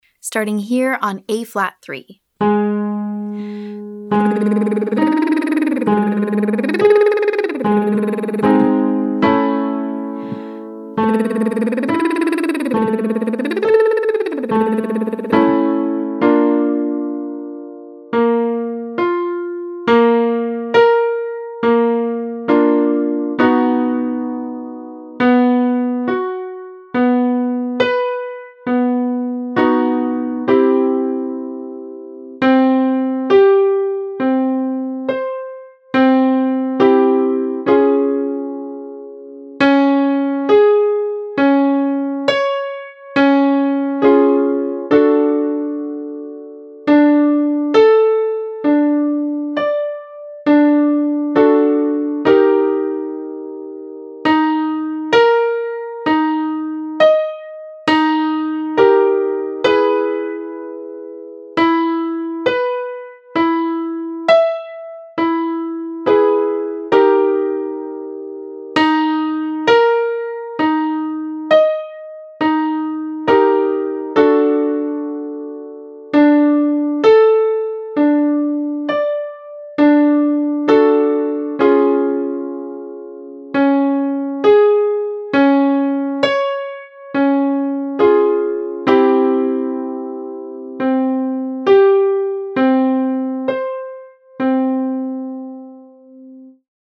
Welcome - Online Singing Lesson
In this standalone tutorial, discover daily vocal agility warmups designed for high voices, including sopranos, mezzos, and altos. We’ll start with an overview of the series, focusing on ascending and descending pentatonic scales.